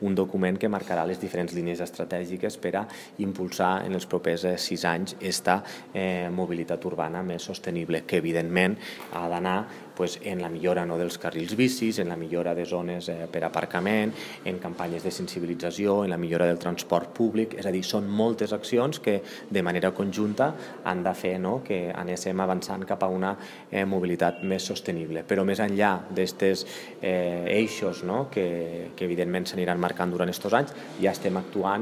Jordi Jordan, alcalde de Tortosa…